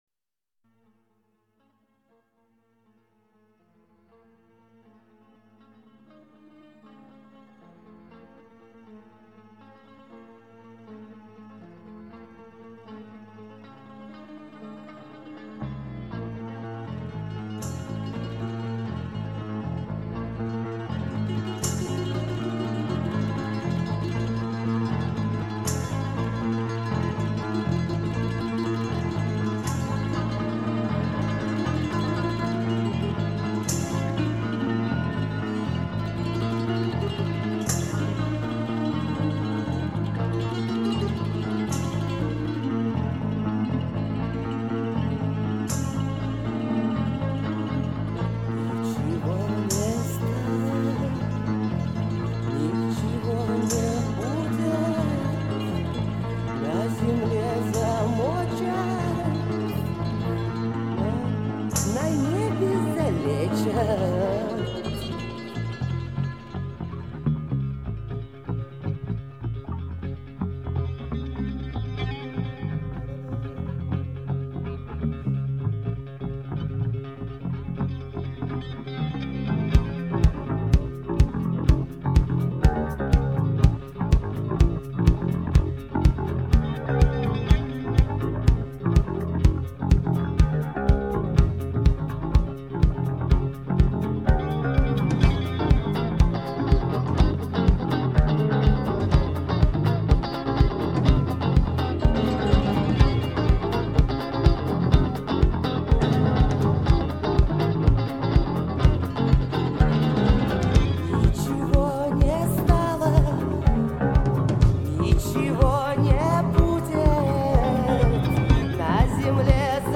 Рок Русский рок